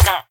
sounds / mob / villager / hit1.ogg